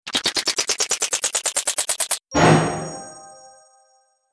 دانلود صدای بمب 11 از ساعد نیوز با لینک مستقیم و کیفیت بالا
جلوه های صوتی